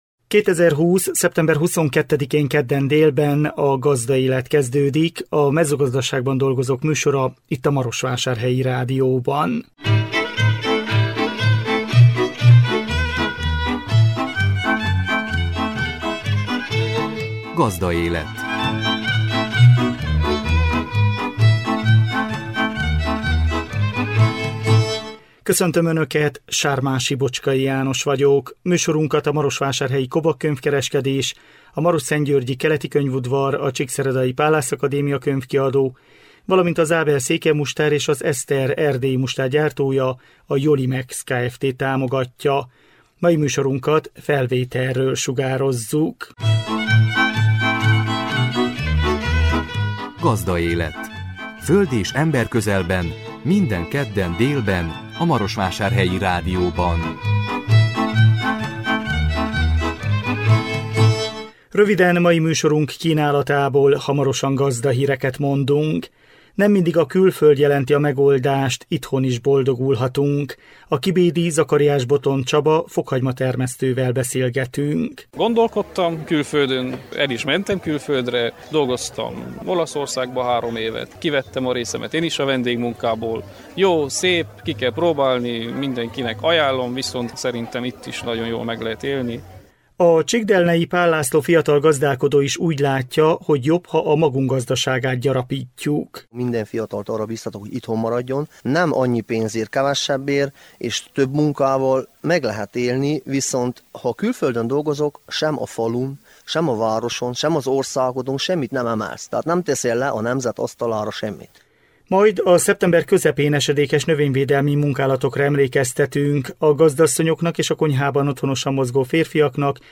Vele készült interjúnk.